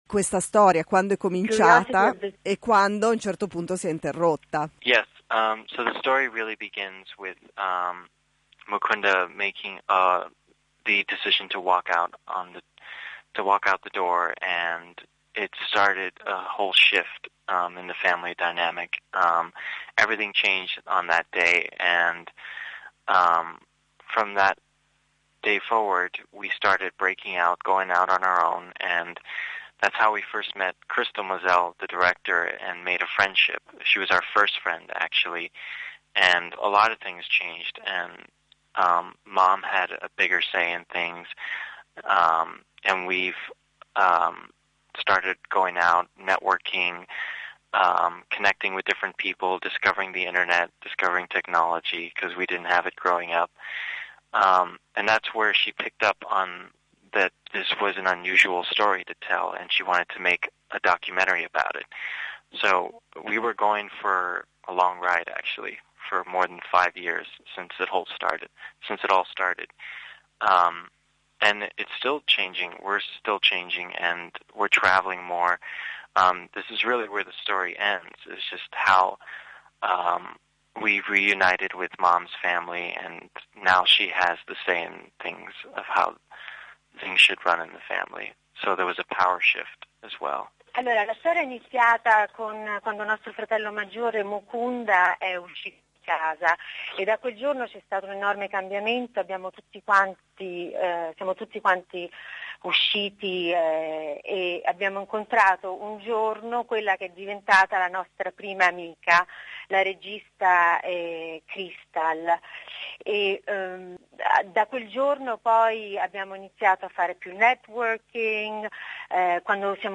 Sono loro i portavoce di tutta la famiglia e a raccontare al microfono di Radio Popolare la propria storia.